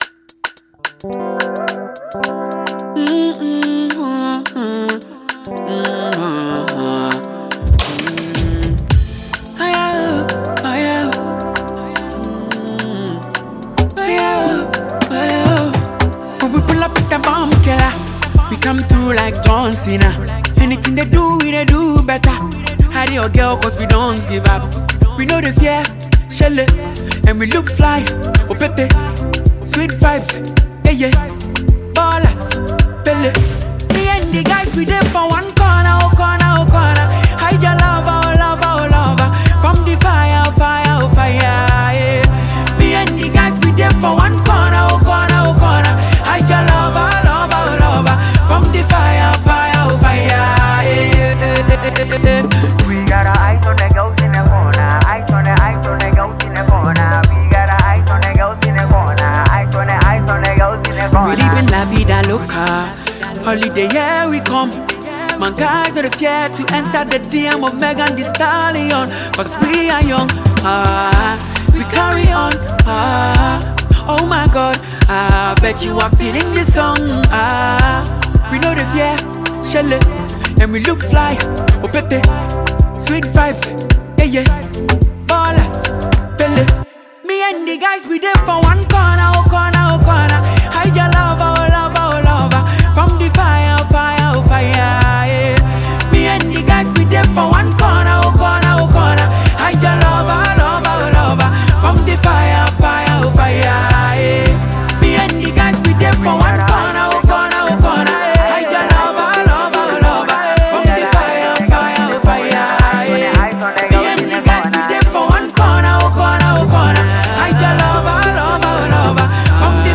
Highlife